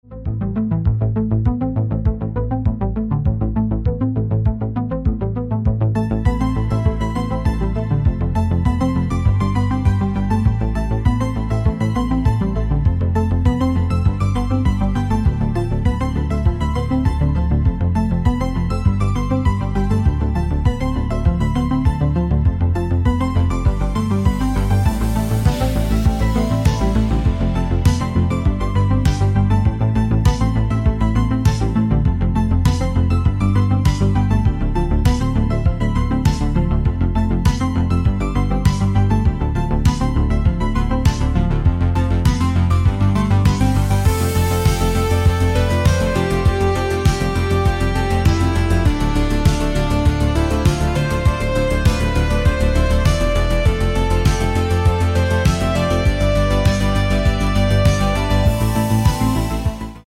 Sphärische klingender Sound
Musik